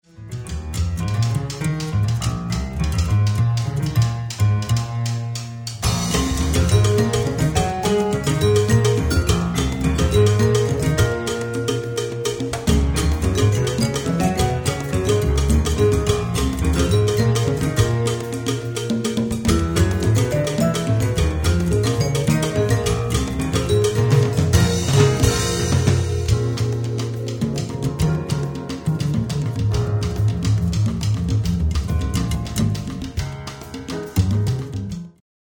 vibes
congas